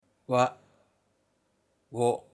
わ行 wa i(wi) e(we) o(wo)